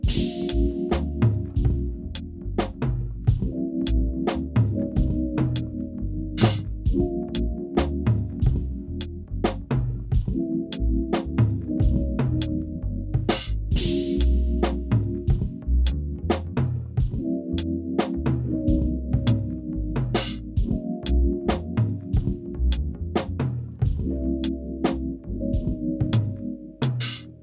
A Boombap lofi vibe sample pack with a jazzy dark ambient aura that make this collection of samples perfect to improvise over and get deep, complex and introspective tracks